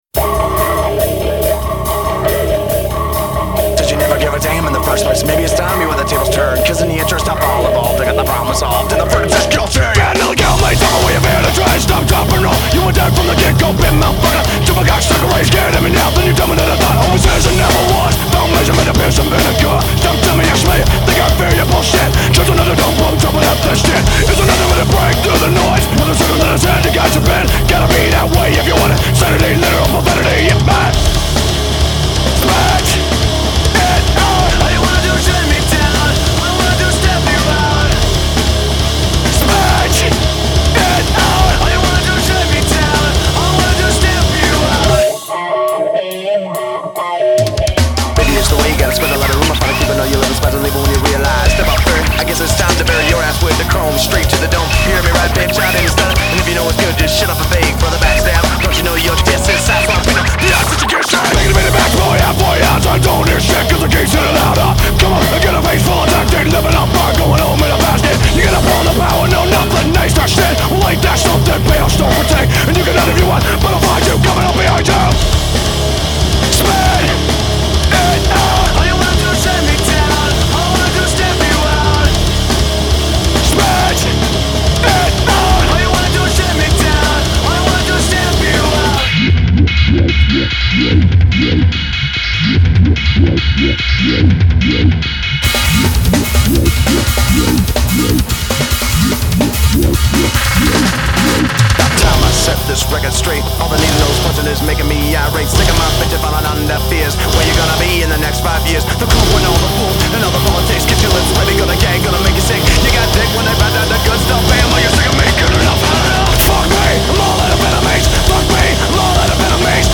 가장 강열한 메탈입니다...